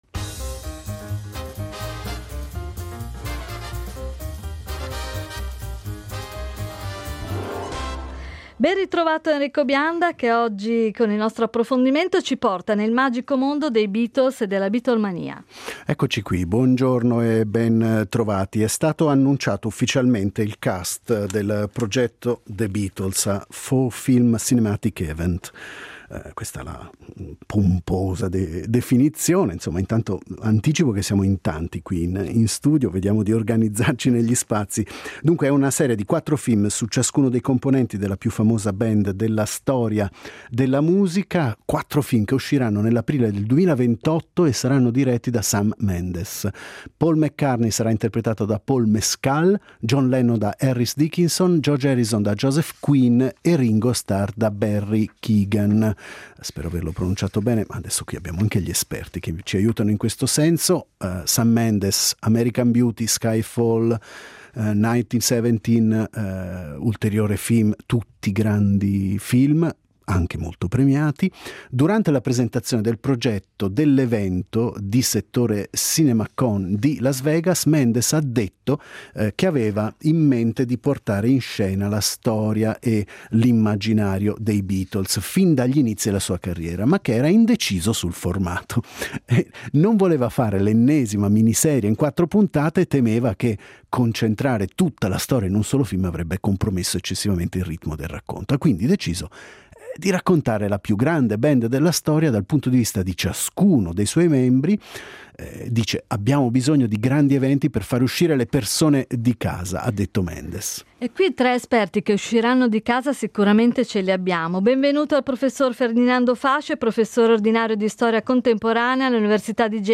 Ad Alphaville abbiamo ospitato tre esperti